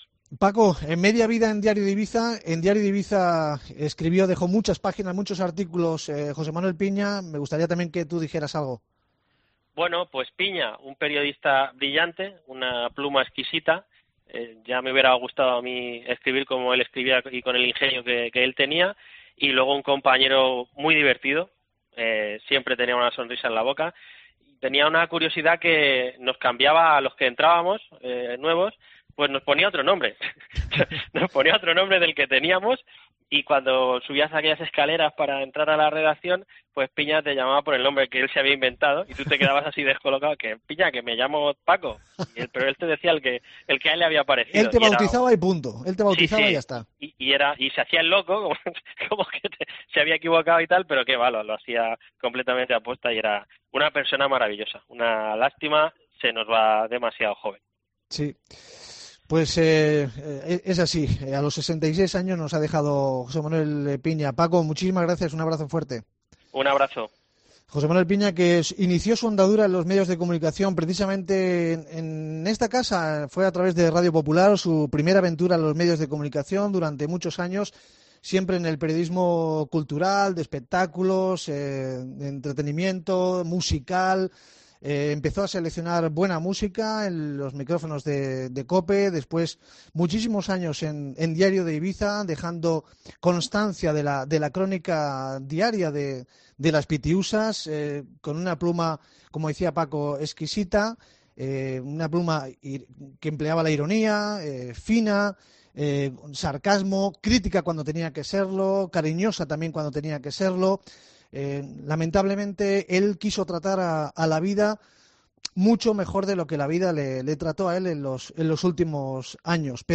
Con este adiós sonoro hemos querido rendir nuestro pequeño tributo a un compañero que nos ha dejado.